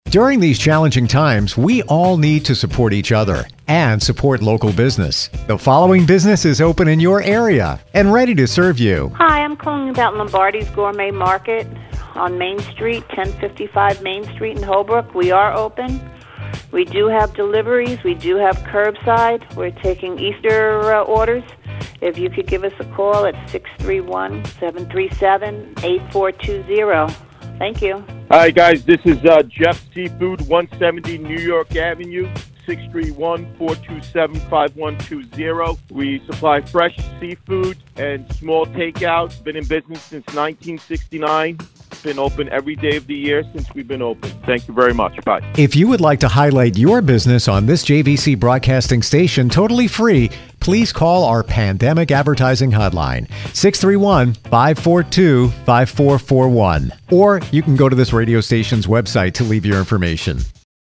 With telephone numbers for its Florida and New York stations set up, JVC is allowing qualifying businesses across its three markets to record a thirty-second voicemail showcasing themselves.